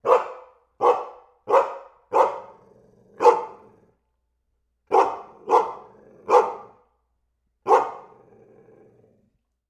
Рычащий лай пса звук